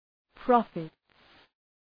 Προφορά
{‘prɒfıts}